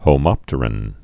(hō-mŏptər-ən)